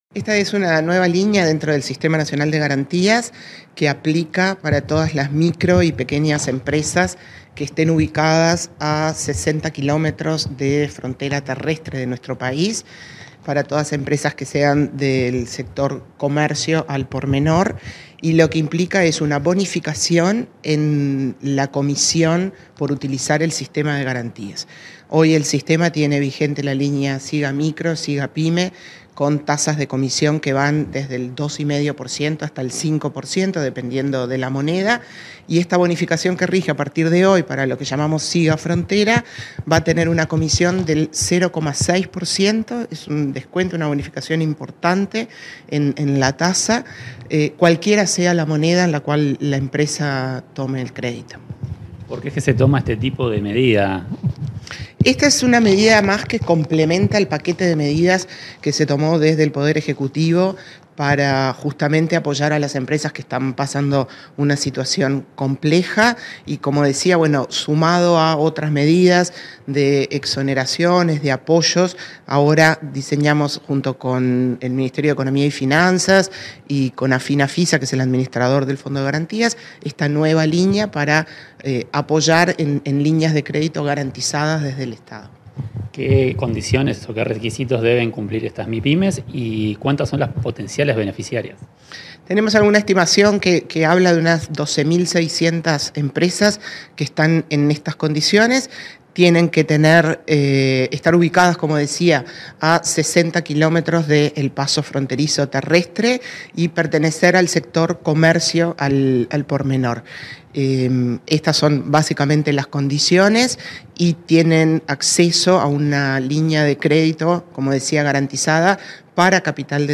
Entrevista a la presidenta de ANDE, Carmen Sánchez
A partir de este jueves 12, las micro, pequeñas y medianas empresas (Mipymes) ubicadas hasta 60 kilómetros de los pasos de frontera terrestre con Argentina y Brasil podrán acceder a garantías del Sistema Nacional de Garantías (SIGa). Comunicación Presidencial dialogó con la presidenta de la Agencia Nacional de Desarrollo (ANDE), Carmen Sánchez sobre esta importante herramienta para las Mipymes.